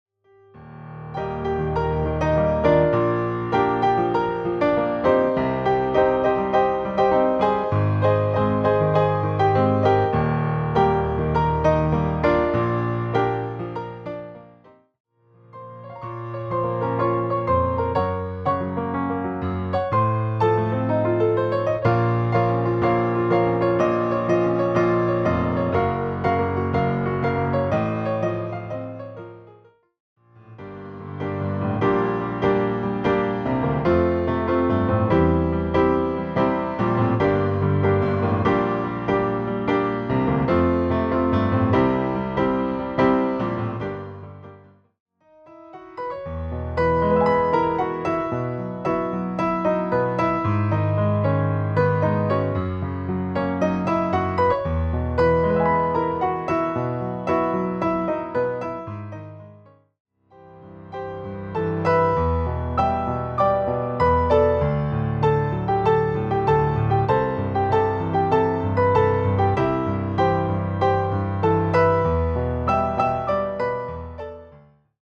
reimagined as solo piano arrangements.
giving familiar songs a calmer, more subdued treatment.